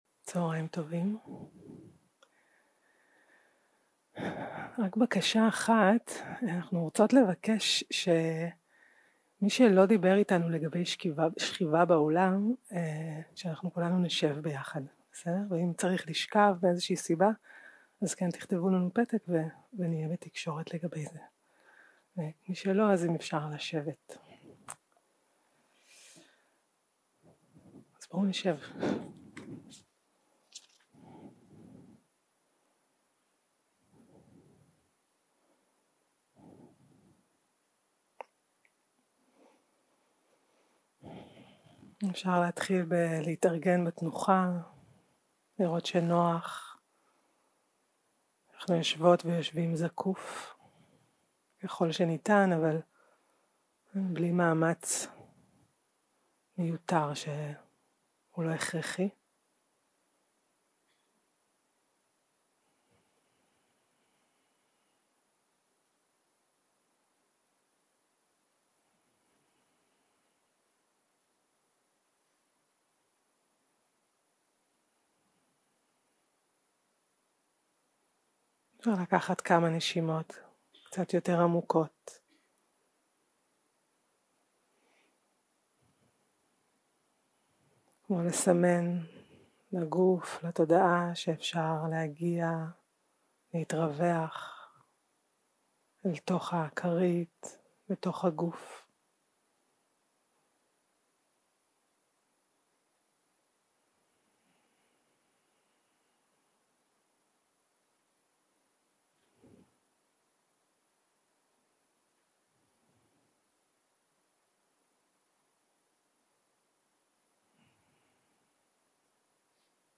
יום 3 - הקלטה 5 - צהרים - מדיטציה מונחית - יחס אוהב לעצמי 0:00 0:00 סוג ההקלטה: Dharma type: Guided meditation שפת ההקלטה: Dharma talk language: Hebrew